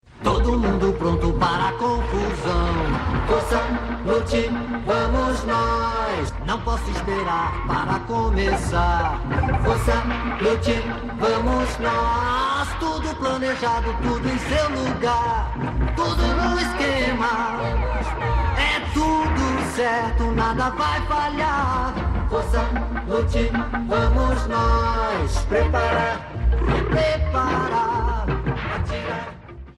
Music Sample